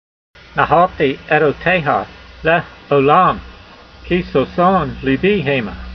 v111_voice.mp3